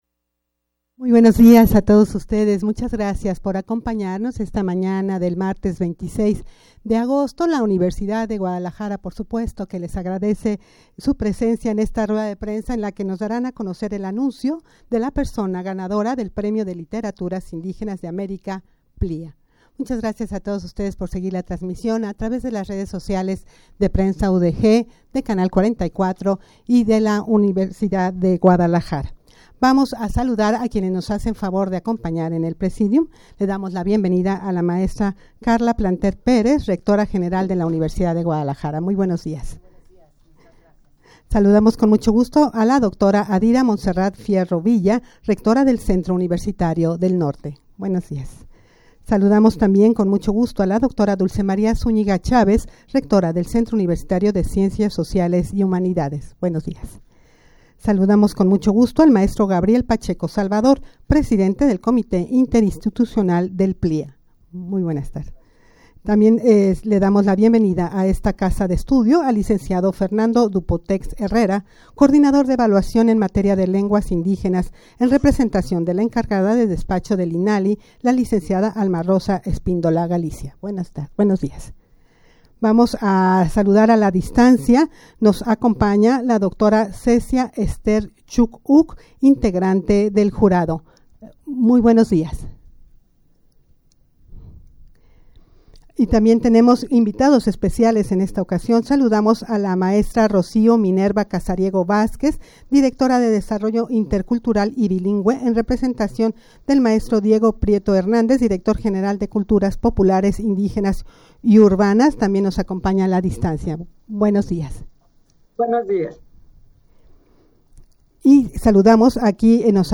Audio de la Rueda de Prensa
rueda-de-prensa-para-dar-el-anuncio-de-la-persona-ganadora-del-premio-de-literaturas-indigenas-de-america-.mp3